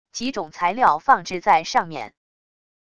几种材料放置在上面wav音频